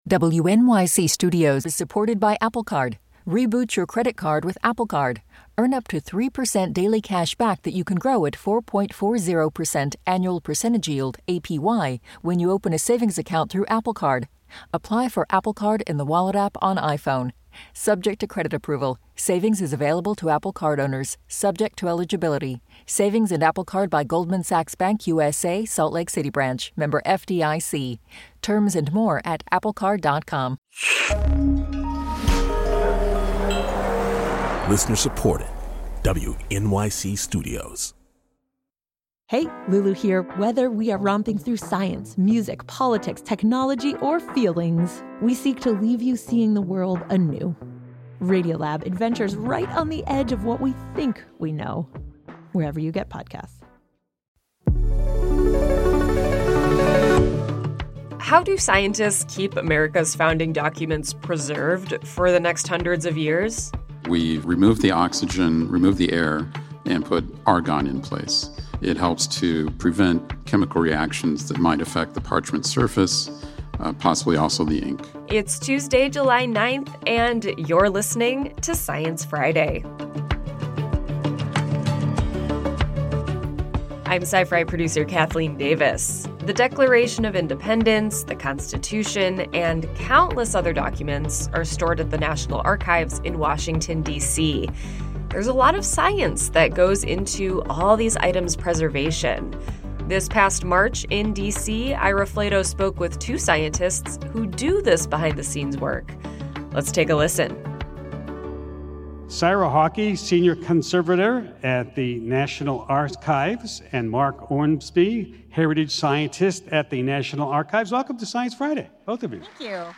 At a live event in Washington, Ira talks to restoration experts about the science of preserving America’s founding documents.